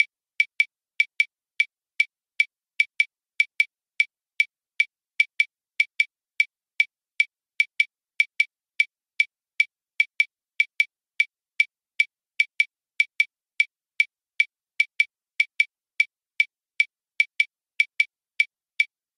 Le Punto Guajiro est construit sur une base ternaire de type 3/4 ou 6/8.
Punto Guajiro (clave)
Clave
Clave avec appui sur les temps
punto_guajiro_clave.mp3